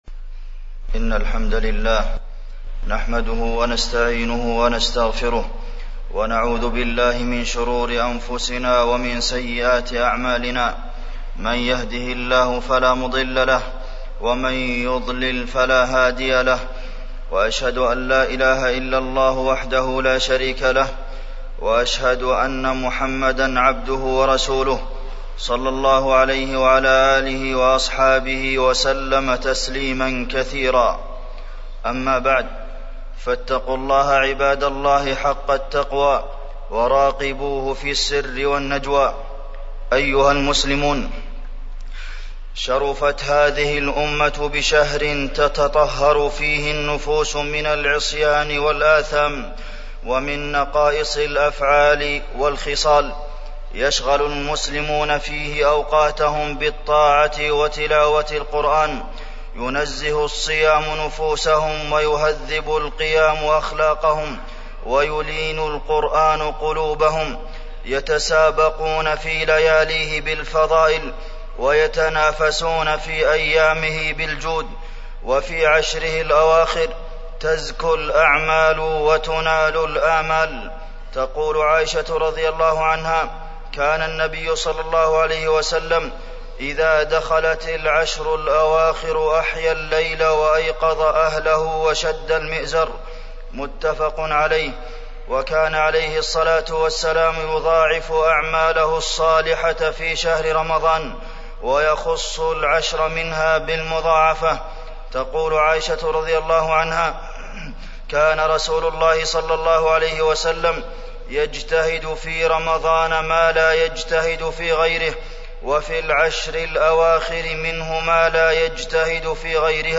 تاريخ النشر ١٨ رمضان ١٤٢٦ هـ المكان: المسجد النبوي الشيخ: فضيلة الشيخ د. عبدالمحسن بن محمد القاسم فضيلة الشيخ د. عبدالمحسن بن محمد القاسم العشر الأواخر من رمضان